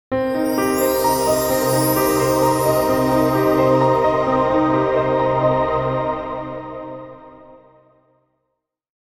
Genres: Sound Logo